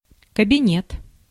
Ääntäminen
Synonyymit правительство офис Ääntäminen Tuntematon aksentti: IPA: /kəbʲɪˈnʲet/ Haettu sana löytyi näillä lähdekielillä: venäjä Käännös Ääninäyte 1. étude {f} France Translitterointi: kabinet.